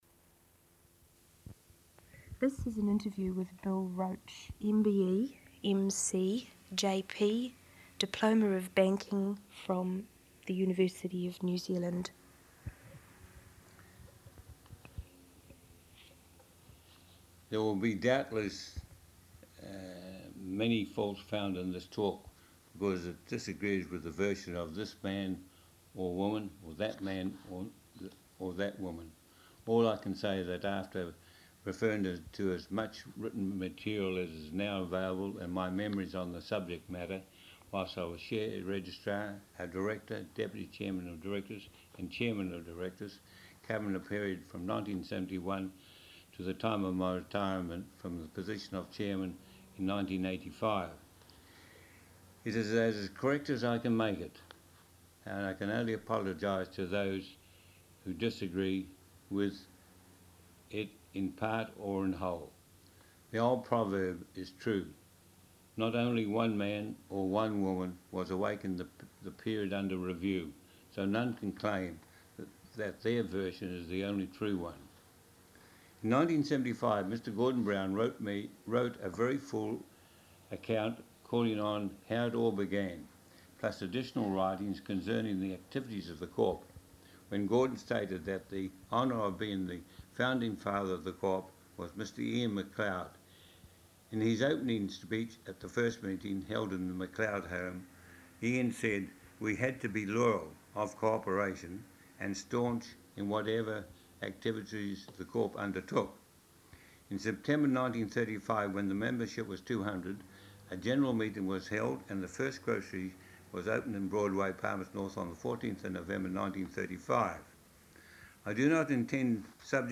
Oral Interview - Consumers' Co-operative Society (Manawatu) Ltd